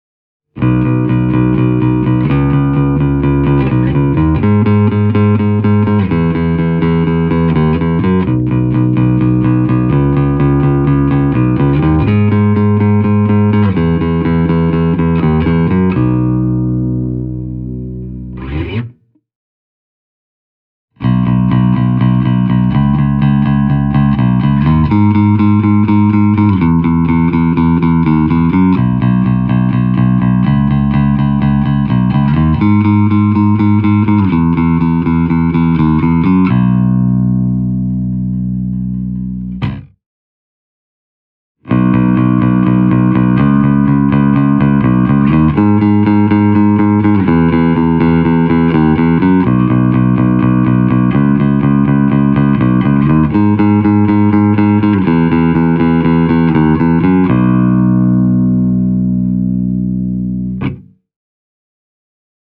With regard to its sound the tiny stack proved to be a thoroughbred Ampeg: The tones on offer are fat and juicy, with the raunchy midrange growl this company’s bass amps are famous for.
The audio clips have been recorded using my ’87 Rickenbacker 4003 (starting with the neck pickup):
Ampeg Micro-CL – pleck